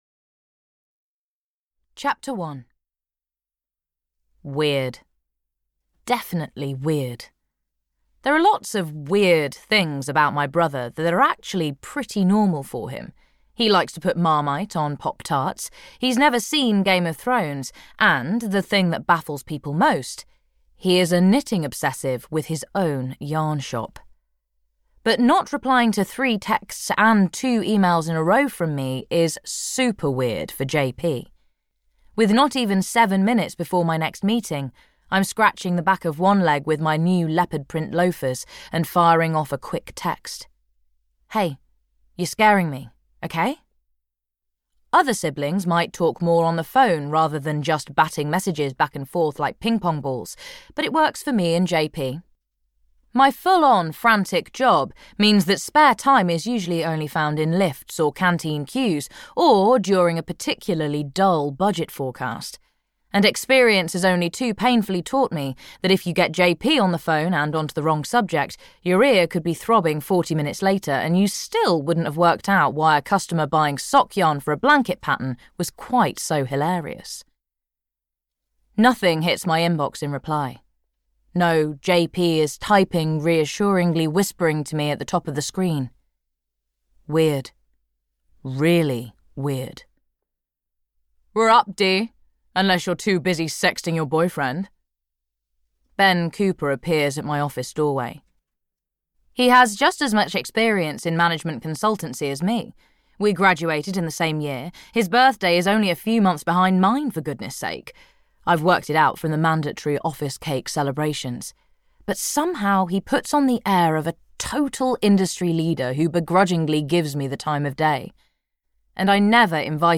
The Woolly Hat Knitting Club (EN) audiokniha
Ukázka z knihy